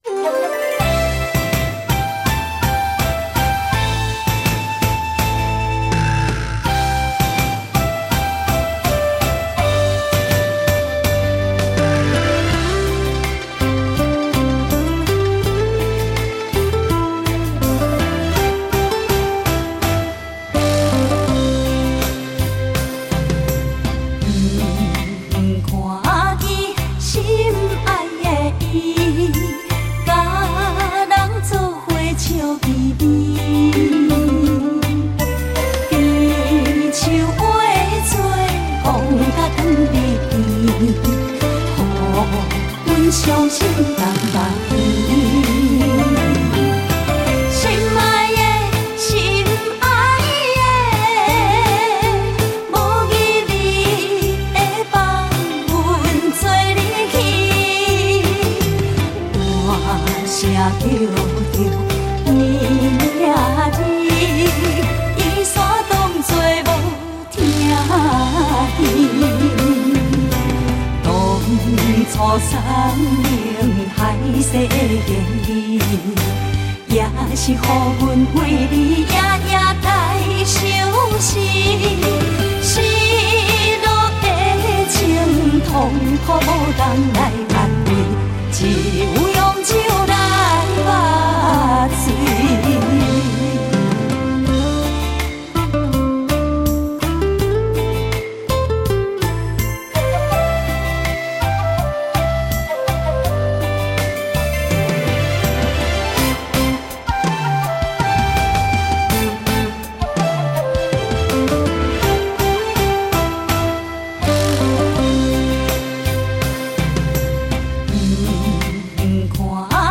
流行闽歌